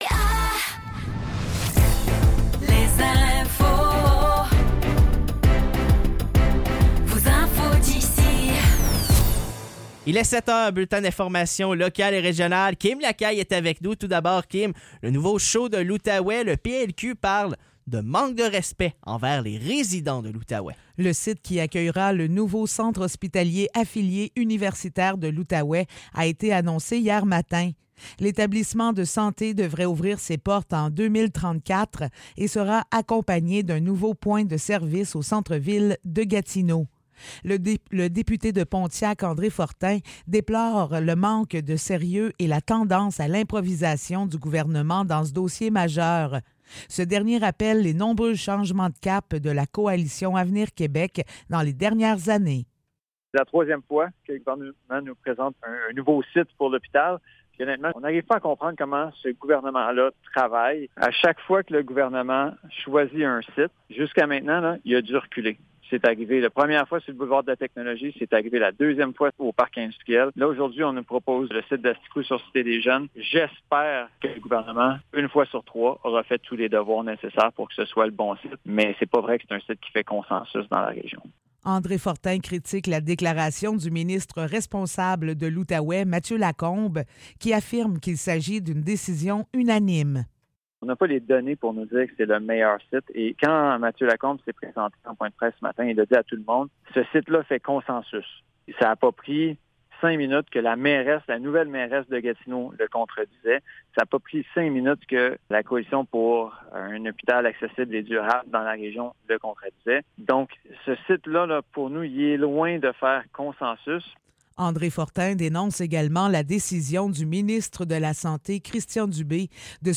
Nouvelles locales - 21 juin 2024 - 7 h